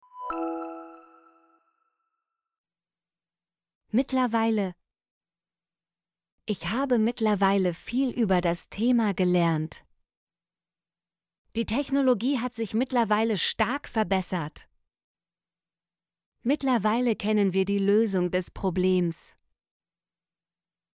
mittlerweile - Example Sentences & Pronunciation, German Frequency List